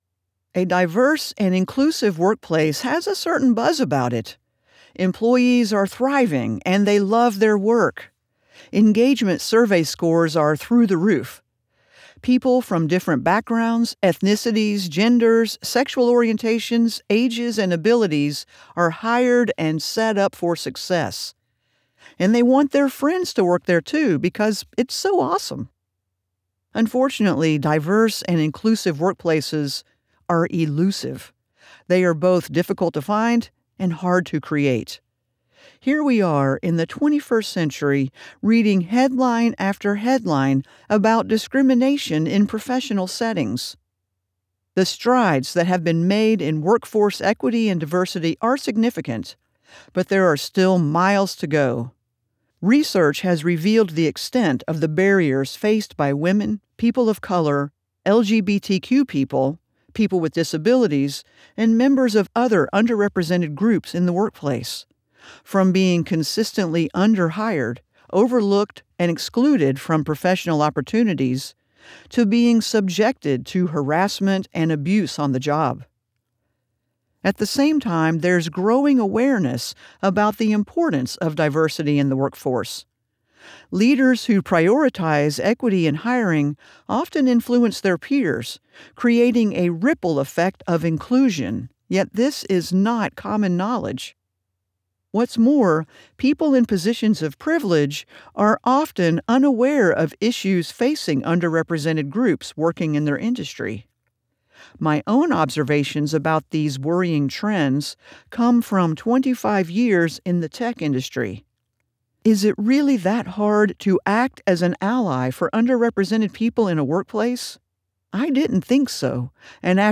Audiobook - Nonfiction Business
English - Southern U.S. English
Appalachian, Southern Georgia coast
Middle Aged